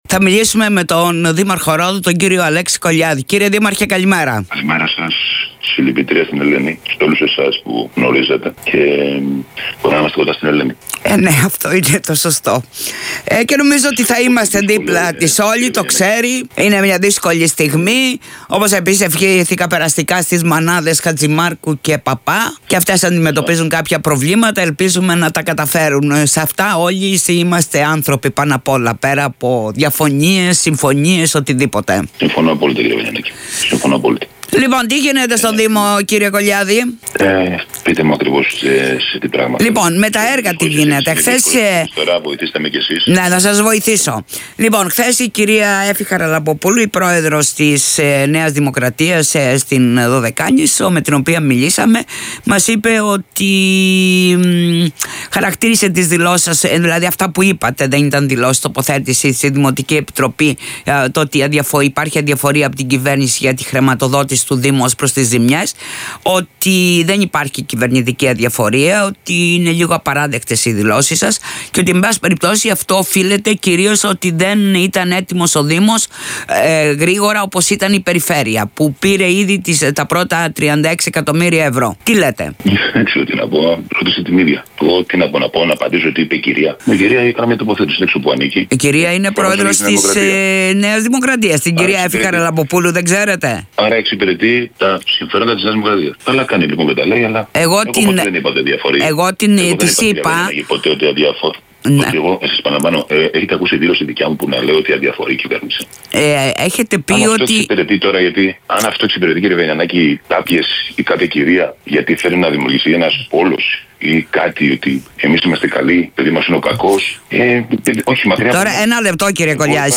Ο δήμαρχος Ρόδου Αλέξης Κολιάδης σε δηλώσεις του σήμερα για τη μη χρηματοδότηση μέχρι σήμερα από την κυβέρνηση στο δήμο Ρόδου, για την αποκατάσταση των ζημιών, εμφανίστηκε με χαμηλούς τόνους λέγοντας, ότι δεν έχει πρόβλημα με κυβέρνηση και βουλευτές αλλά διεκδικεί μαζί με τους τοπικούς φορείς το μερίδιο που αναλογεί στο νησί.